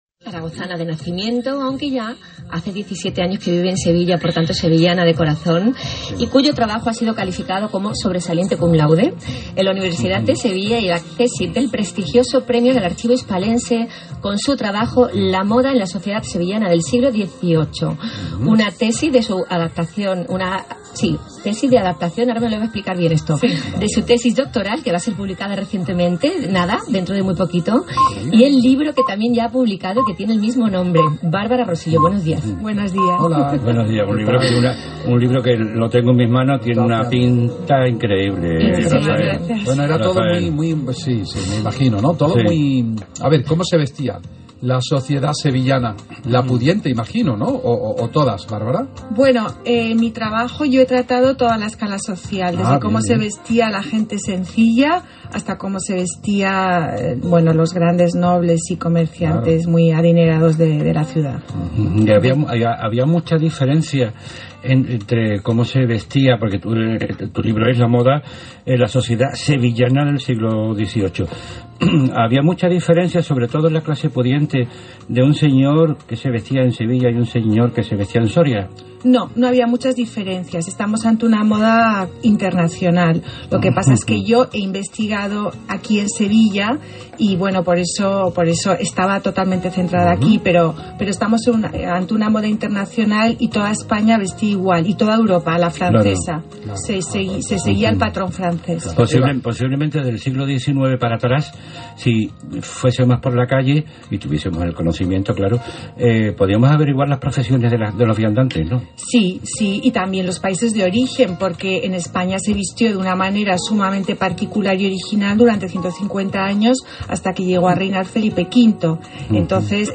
Entrevista en el programa Aquí Estamos de Canal Sur radio